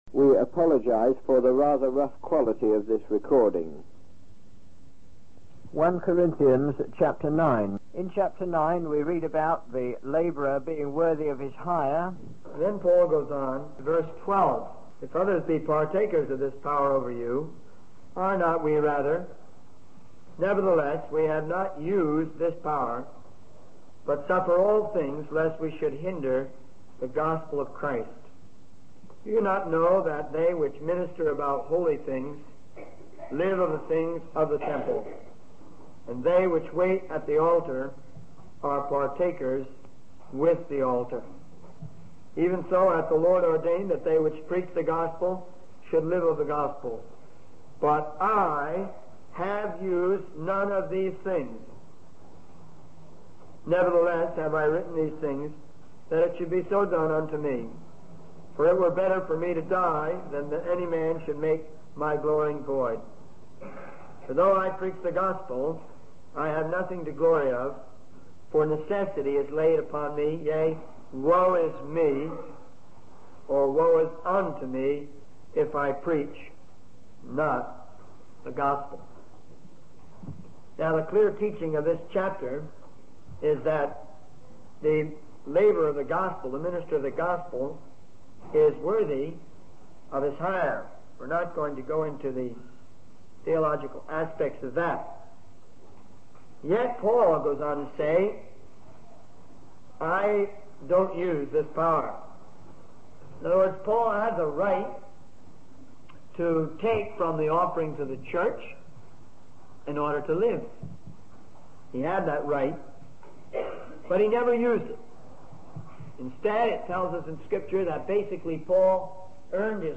In this sermon, the speaker emphasizes the importance of following five principles in preaching the word of God. These principles include being enthusiastic, learning the product thoroughly, practicing effective communication techniques, demonstrating sincerity, and considering the needs of the person being spoken to.